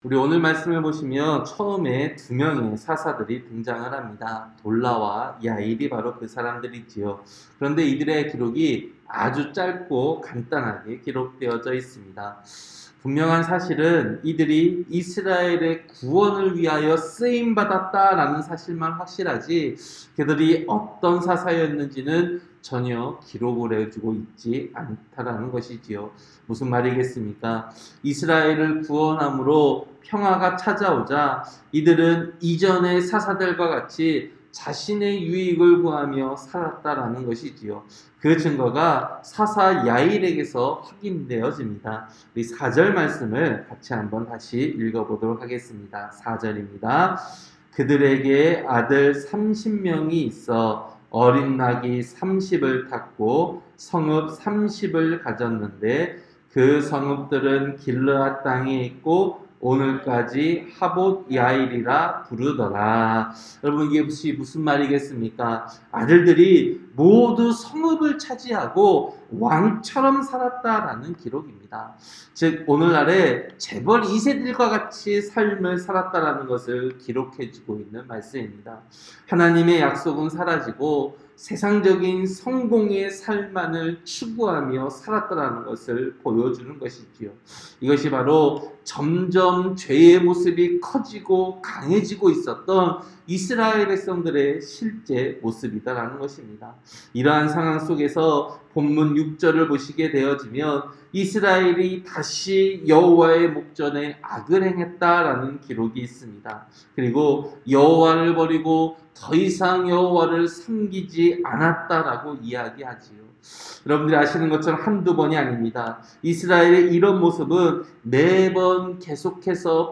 새벽설교-사사기 10장